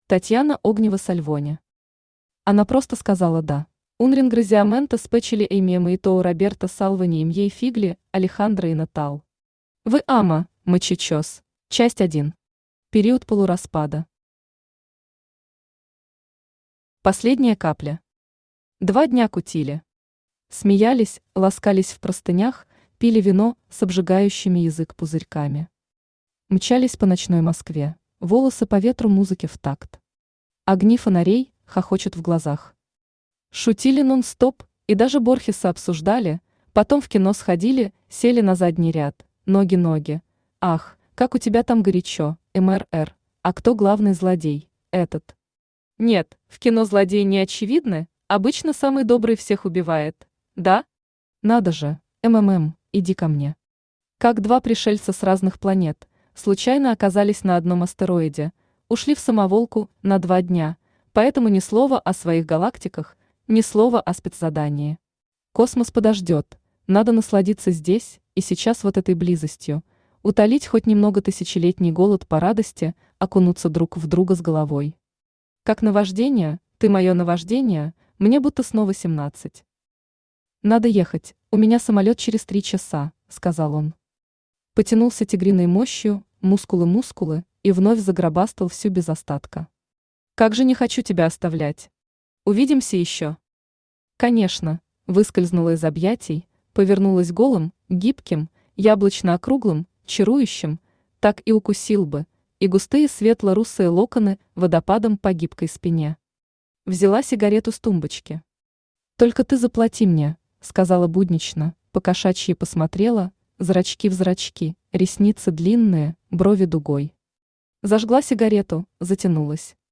Аудиокнига Она просто сказала «Да» | Библиотека аудиокниг
Aудиокнига Она просто сказала «Да» Автор Татьяна Александровна Огнева-Сальвони Читает аудиокнигу Авточтец ЛитРес.